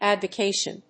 /ˌædvʌˈkeʃʌn(米国英語), ˌædvʌˈkeɪʃʌn(英国英語)/
フリガナアドバケイシャン